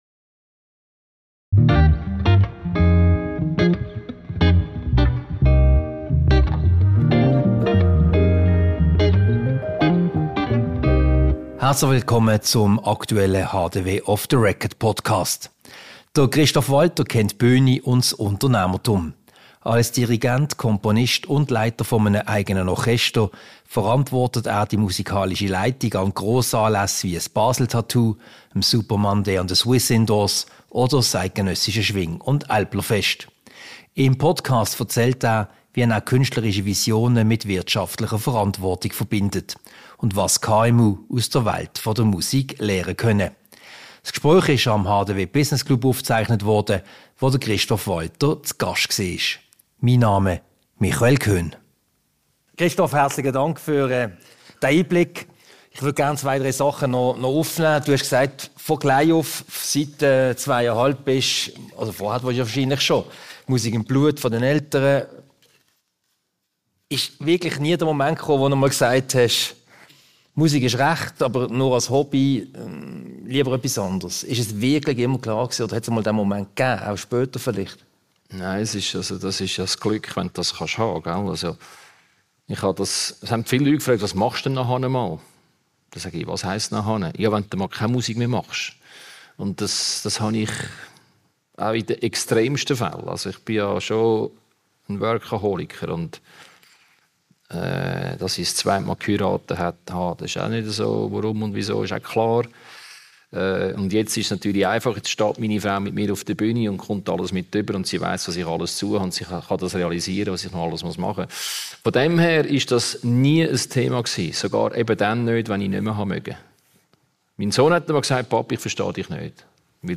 Ein Podiumsgespräch über berühmte Schwinger-Hymnen und Leidenschaft, mit Einblicken in persönliche Geschichten rund um Armee, Musik und das Leben Diese Podcast-Ausgabe wurde anlässlich des HDW Business Clubs vom 10. September 2025 im Haus der Wirtschaft.